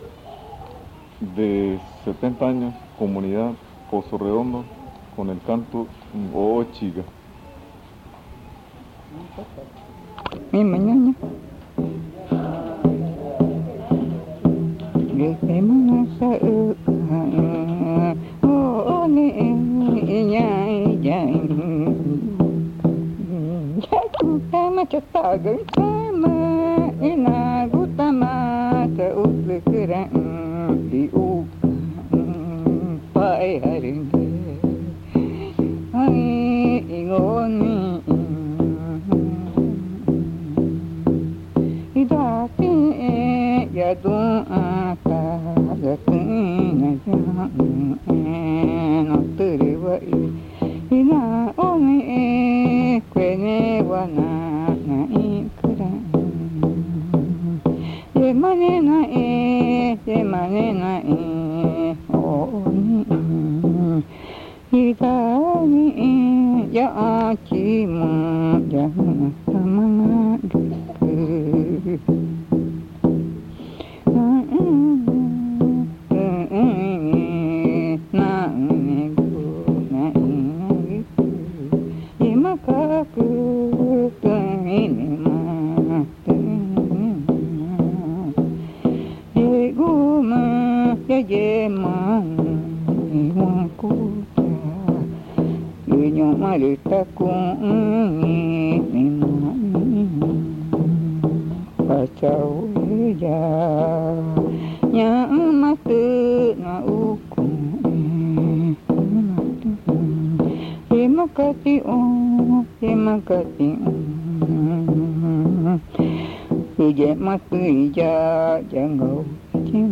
Pozo Redondo, Amazonas (Colombia)
La abuela canta en Magütá sobre las garrapatas malignas que pueden matar a las personas, dando como ejemplo a un cazador que no creía en su existencia y que fue asesinado por ellas. Cantando, la abuela aconseja a la muchacha de la Pelazón (worekü) que tenga cuidado con ellas.
The elder sings in Magütá about evil ticks that can kill people, giving the example of a hunter who did not believe in their existence and was killed by them.